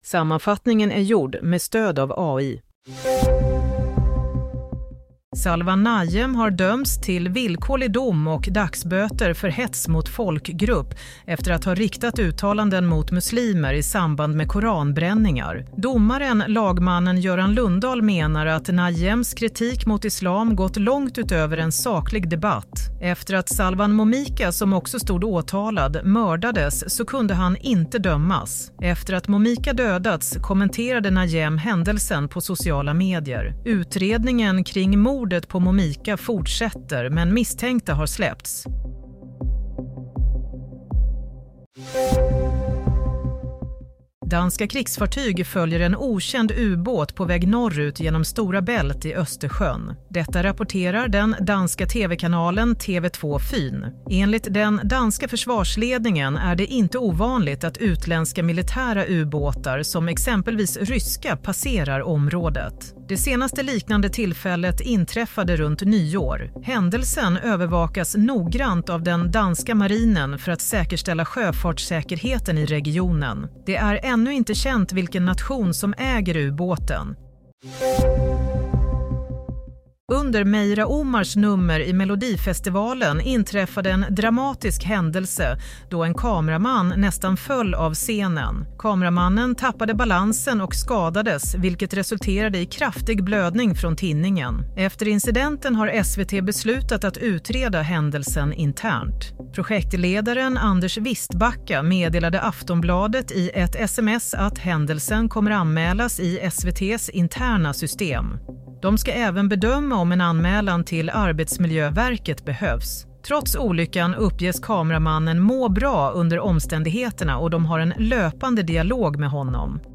Nyhetssammanfattning – 3 februari 14:30